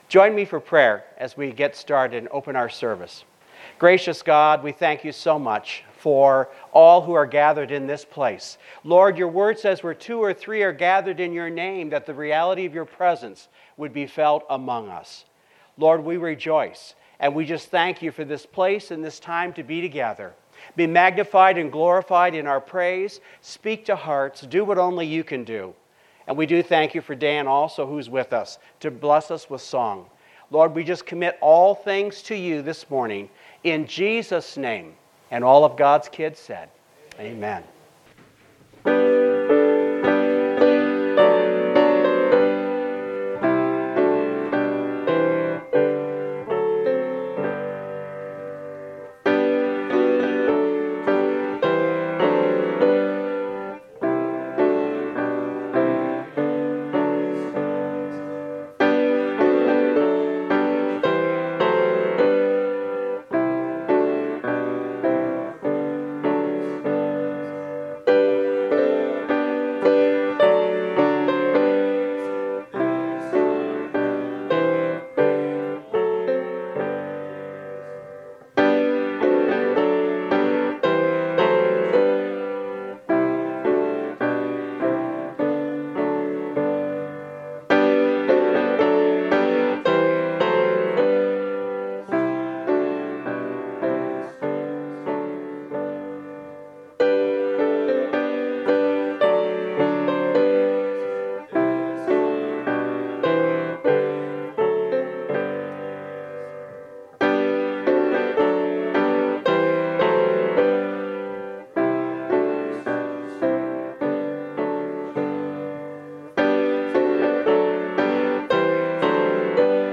Topical Sermons